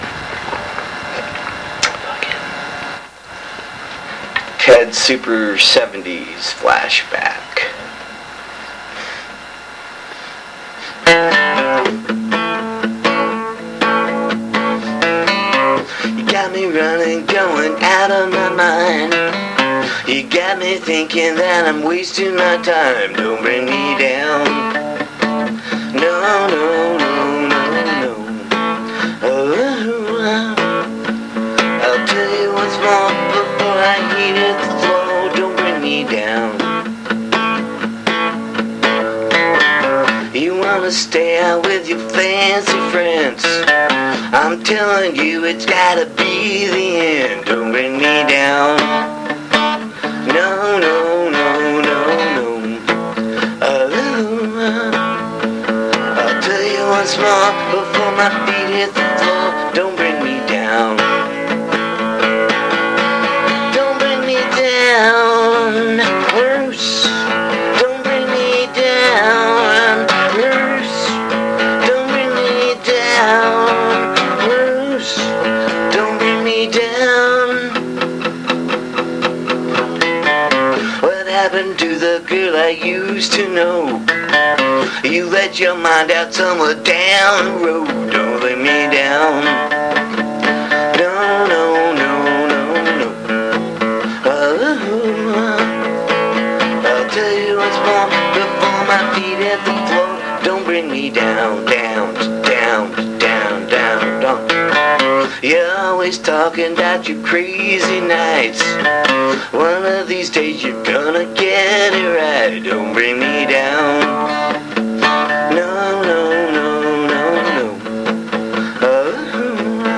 It is kinda funny to me so this is my silly rendition.